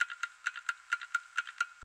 File:ClockTickLoop.ogg